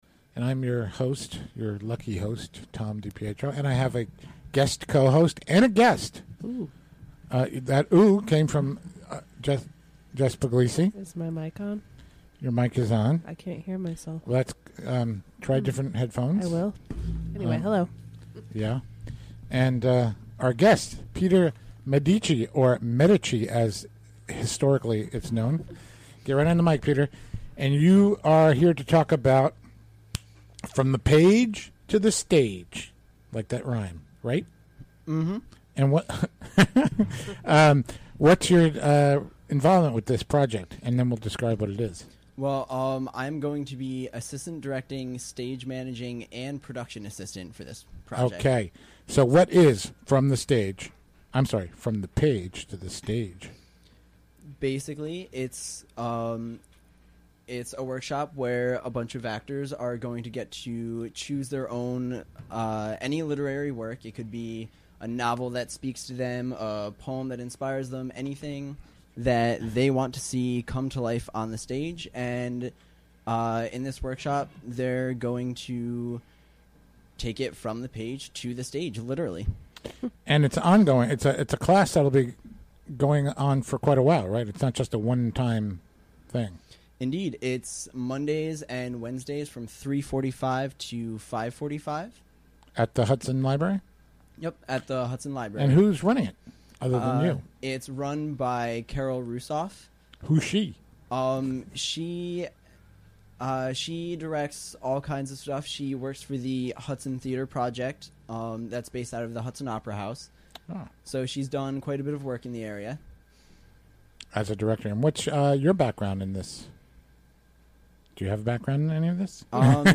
Recorded during the WGXC Afternoon Show Thursday, September 15, 2016.